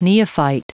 Prononciation du mot neophyte en anglais (fichier audio)